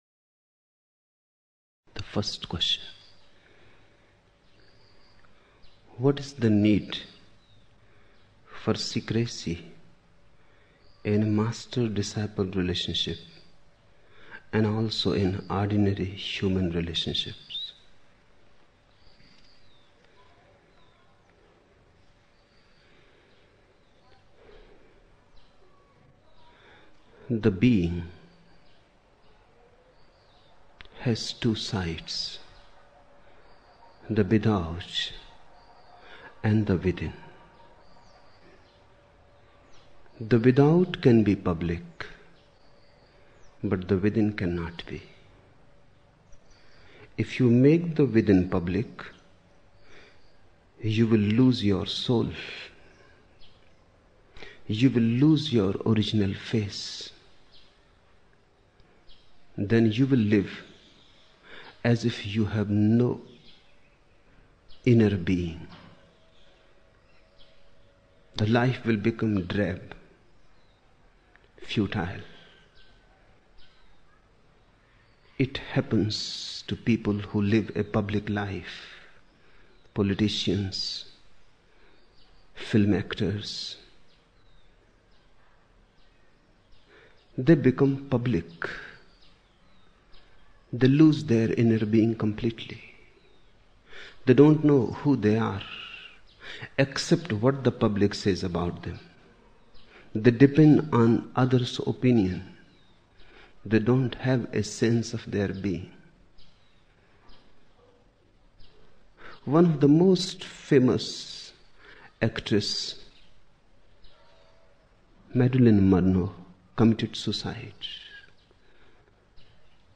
26 October 1975 morning in Buddha Hall, Poona, India